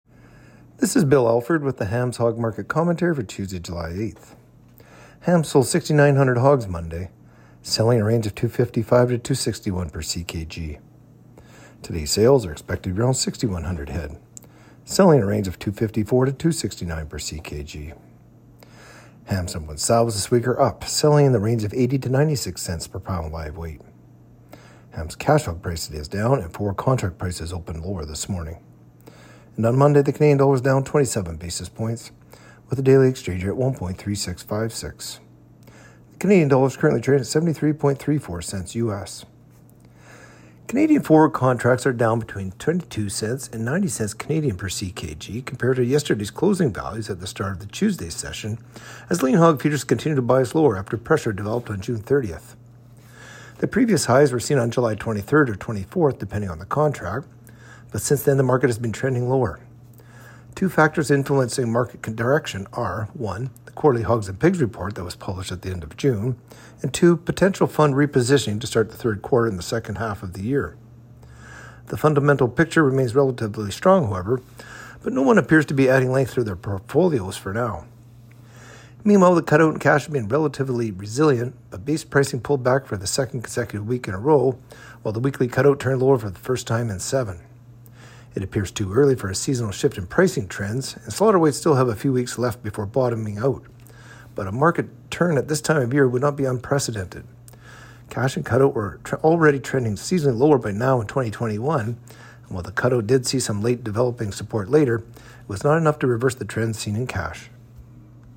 Hog-Market-Commentary-Jul.-8-25.mp3